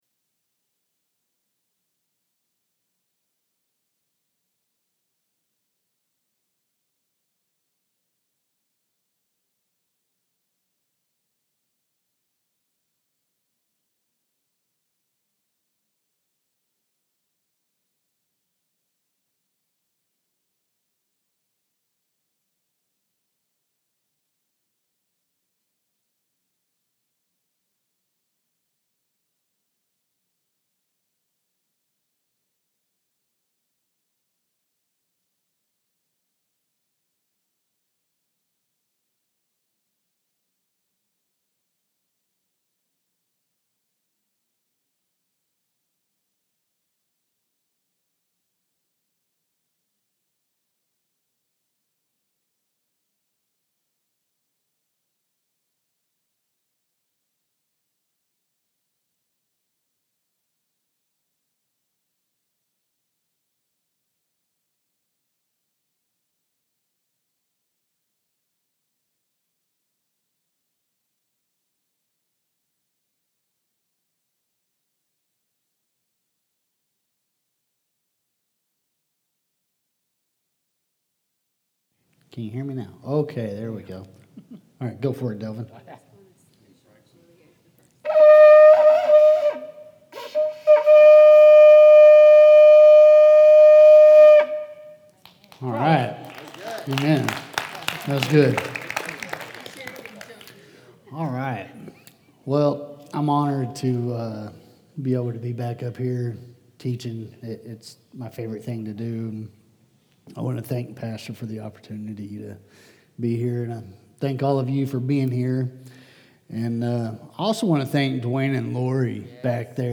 Sermons | The Assembly Heber Springs